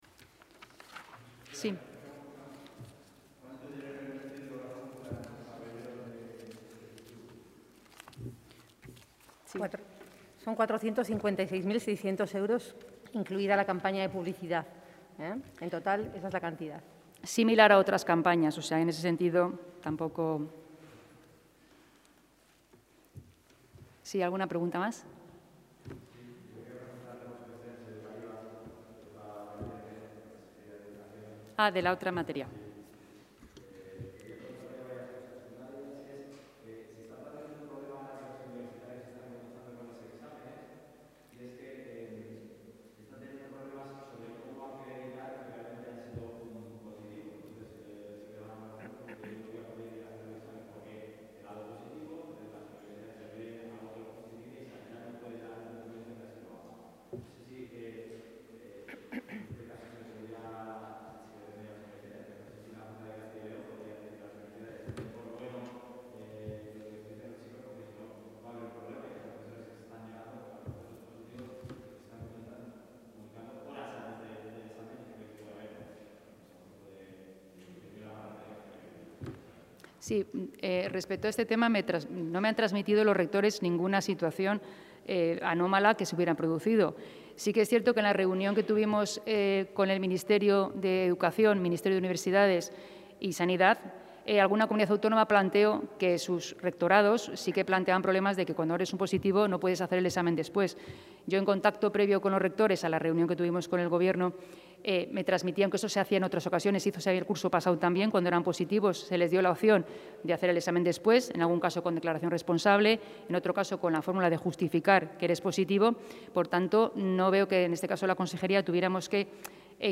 Intervención de la consejera.